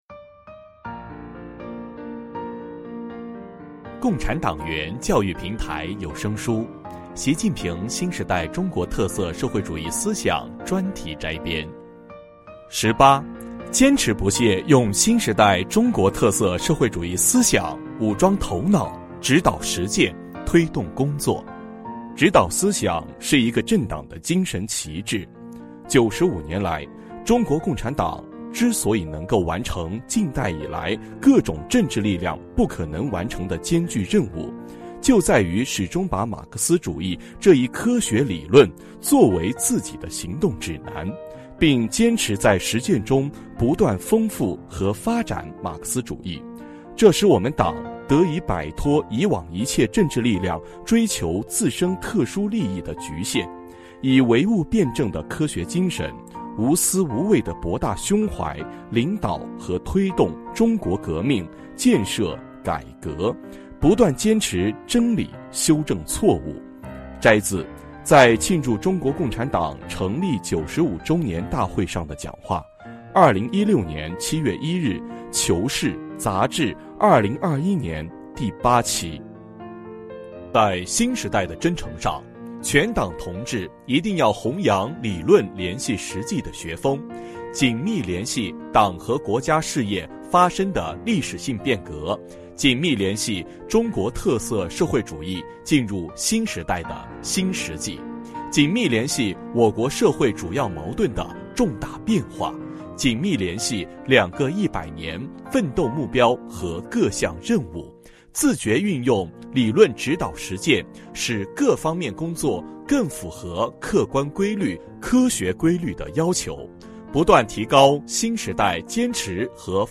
主题教育有声书 《习近平新时代中国特色社会主义思想专题摘编》（85）.mp3